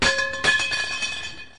/files/sounds/material/metal/pipe/pipe01.mp3
pipe01.mp3